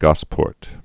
(gŏspôrt)